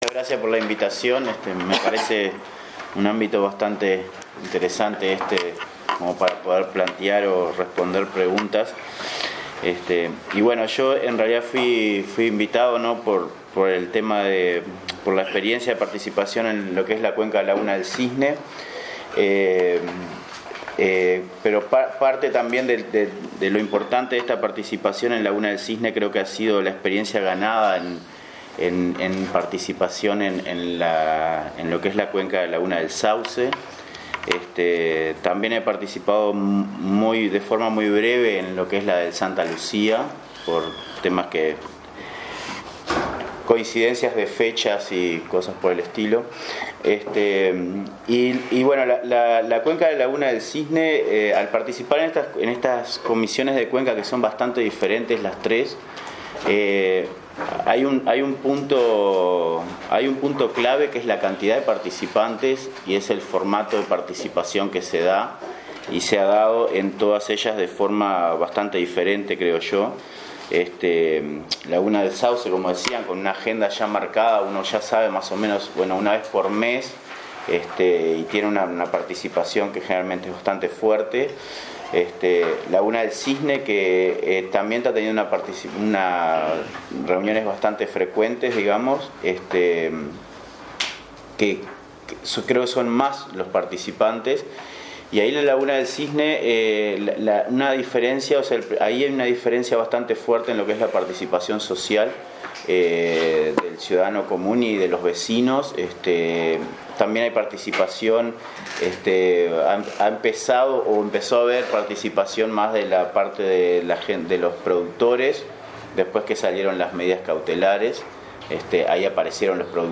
Cada expositor dispuso de 15 minutos. Luego los integrantes del Panel Ciudadanos les hicieron preguntas que fueron respondidas por el expositor.